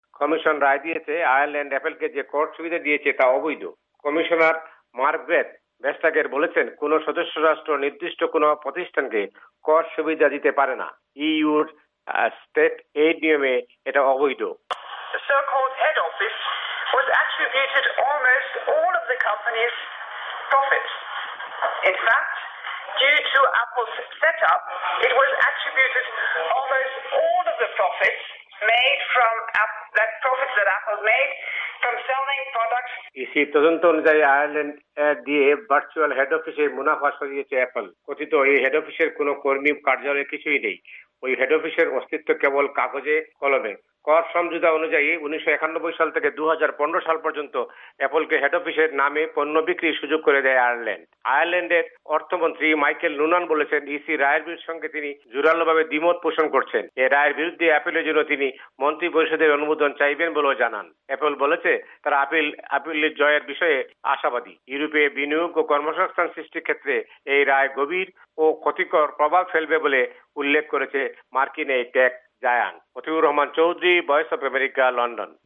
রিপোর্ট (ইইউ)